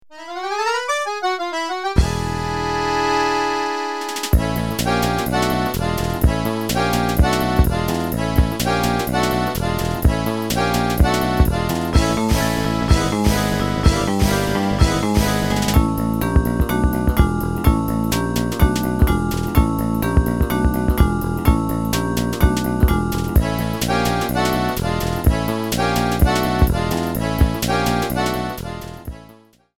Fade-out added